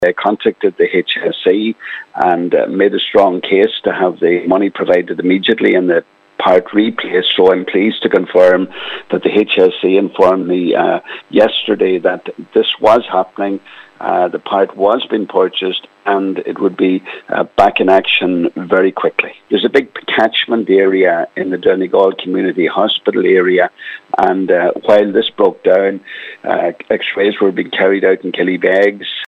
Deputy Pat the Cope Gallagher says he is pleased to welcome the news from the HSE …